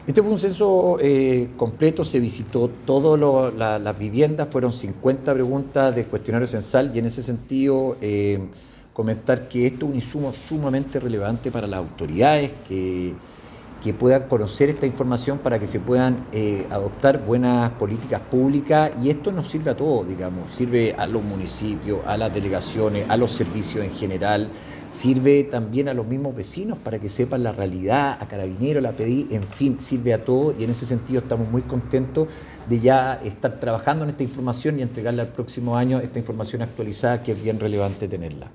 Sergio Zuluaga, Director Regional del INE Los Lagos, comentó que el análisis de los datos recopilados permitirá avanzar en la creación de nuevas políticas públicas, cuya información actualizada estará disponible dentro de los próximos meses.